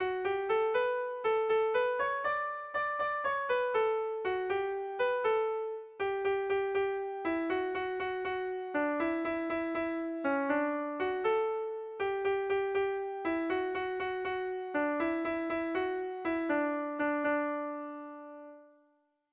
Dantzakoa
AB